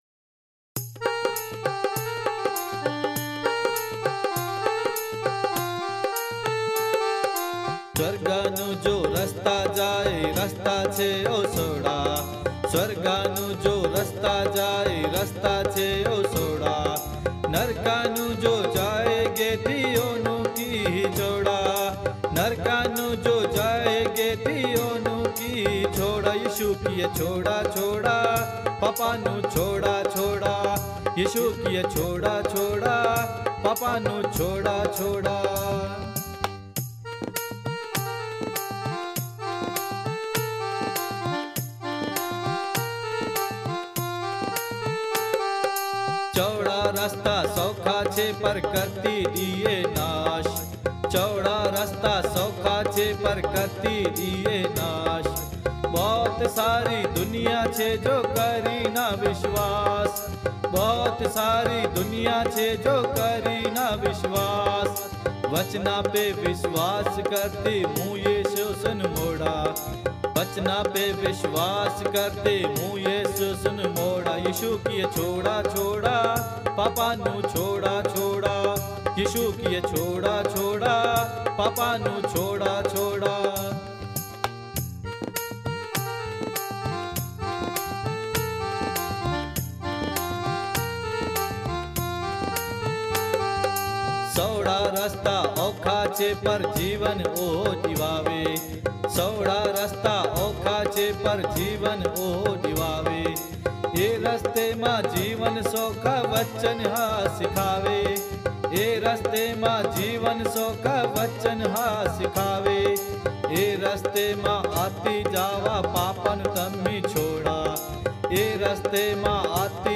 मसीही गीत | ओडराजपूत